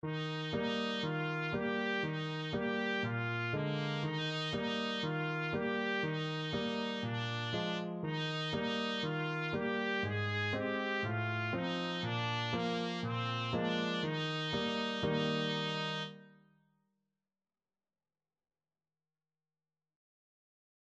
Trumpet
Traditional Music of unknown author.
Eb major (Sounding Pitch) F major (Trumpet in Bb) (View more Eb major Music for Trumpet )
4/4 (View more 4/4 Music)